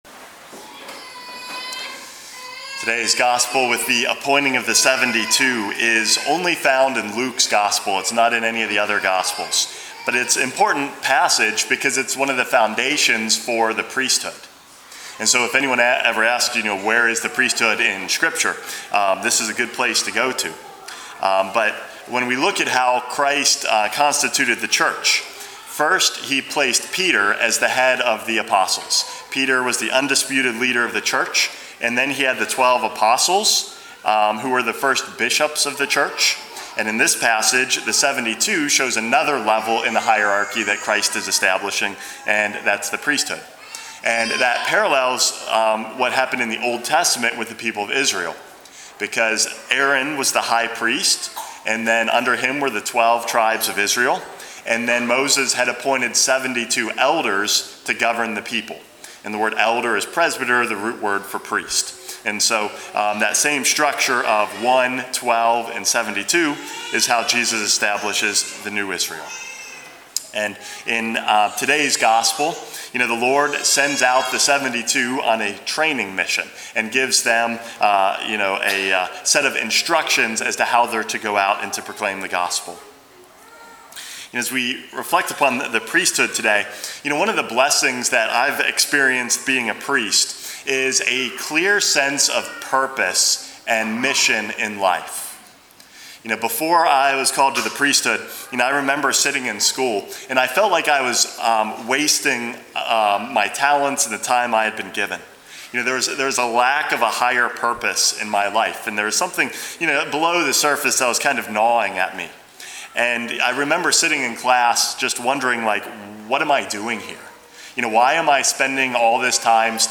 Homily #456 - A Mission